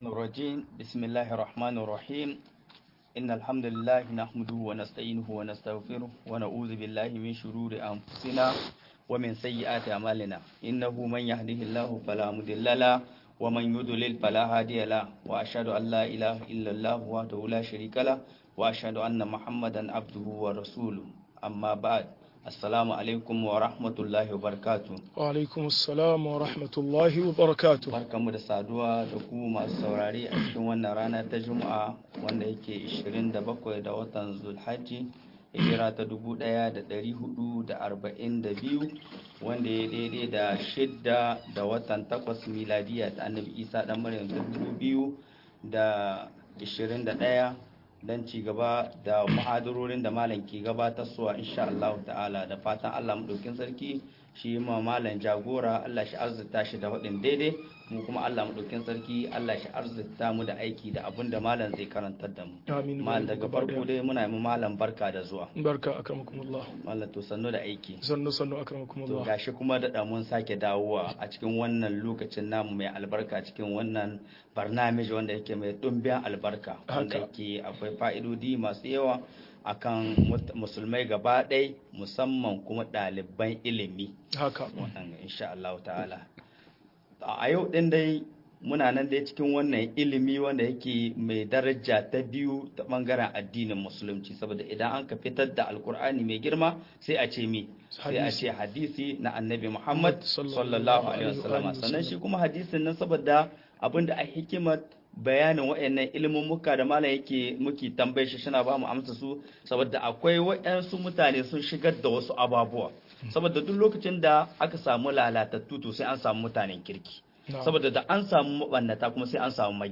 Nau'ukan ilimin hadisi - MUHADARA